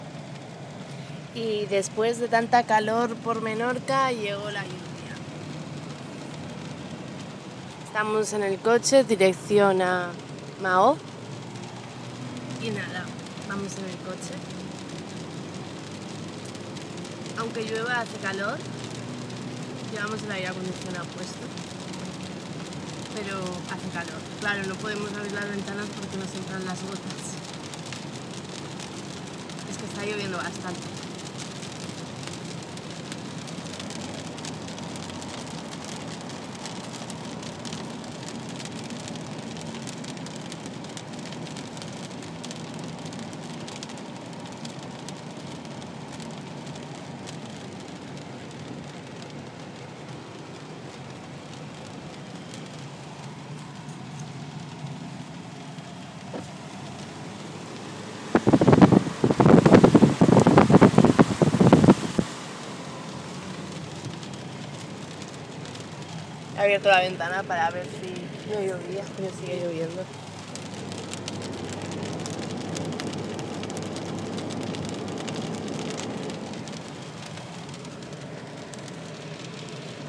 Menorca: lluvia veranie desde elcoche